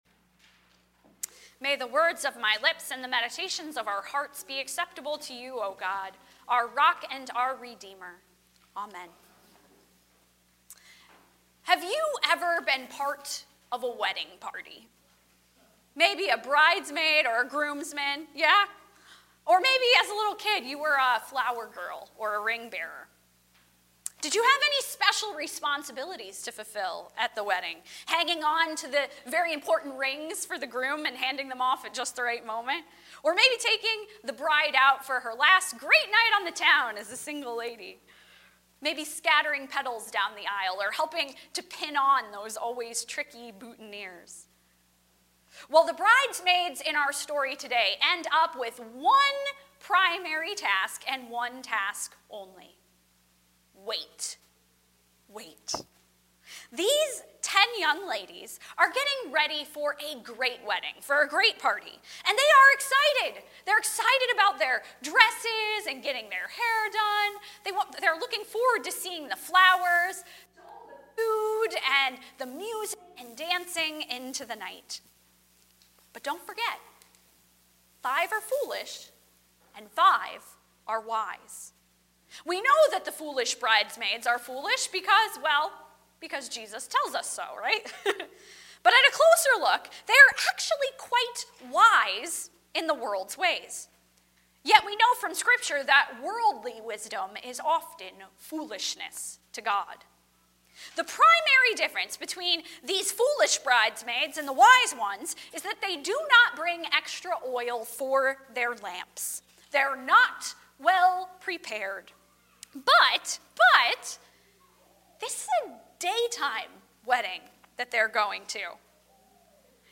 Weekly Services
Service Type: Sunday Morning 10:30